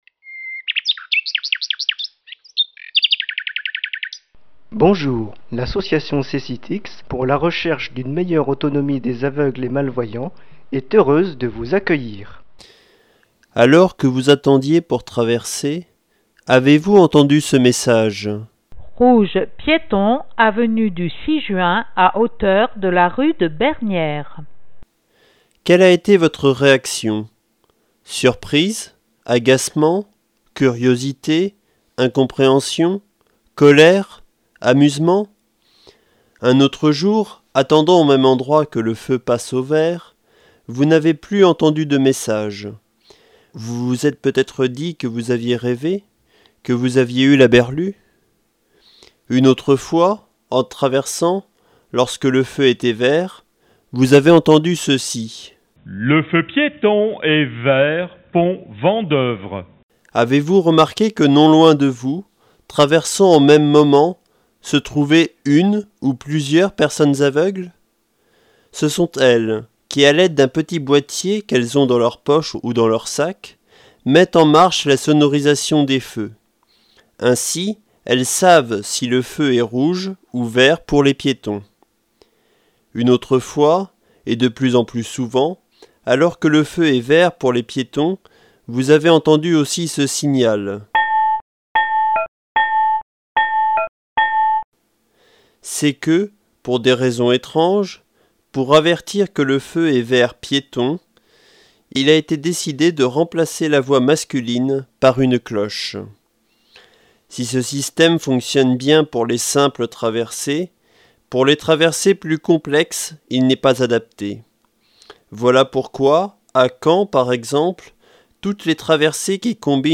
chronique sur les feux sonores
Chronique diffusée le mercredi 5 octobre 2005 sur les ondes de RCF